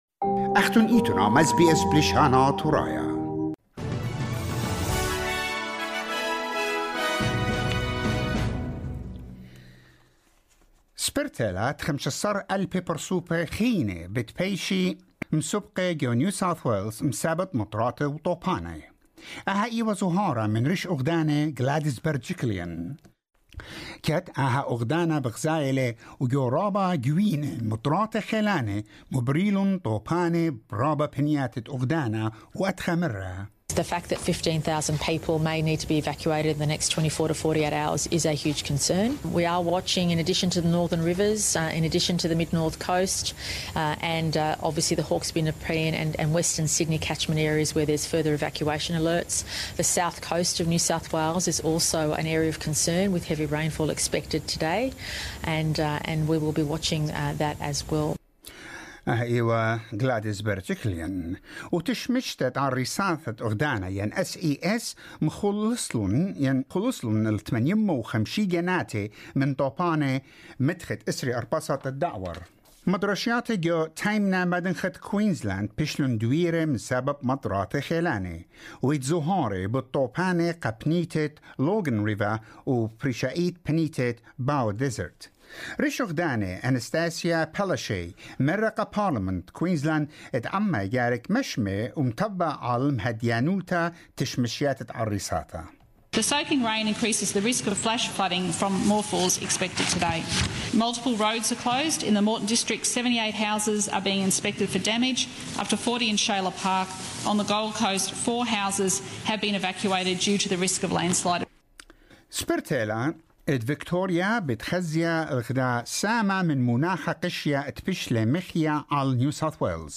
SBS NEWS IN ASSYRIAN